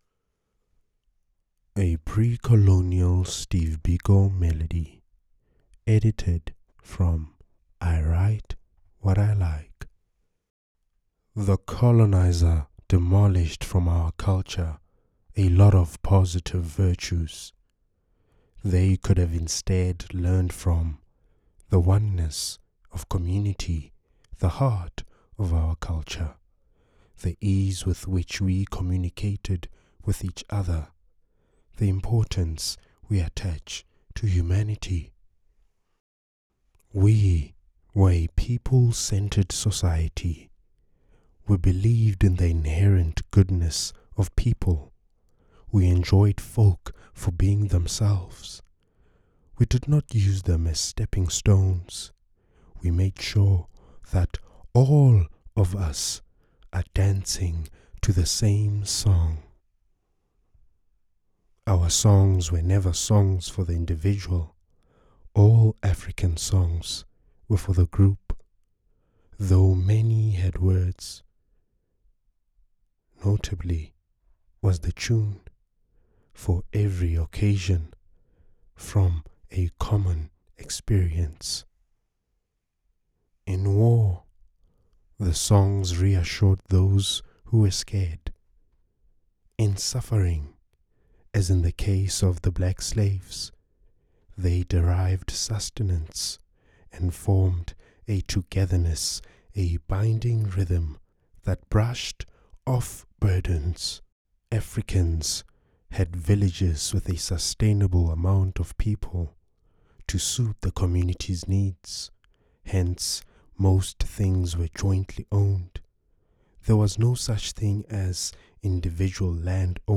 An integral audio component features the evocative words of South African writer Steve Biko, intertwining poetry with the lived experience of pre-colonial lifestyles and animistic traditions. This layering of voice and physical presence underscores the work’s aim: to bridge contemporary existence with ancient wisdom, illuminating the cyclical nature of life and the enduring legacy of civilizations.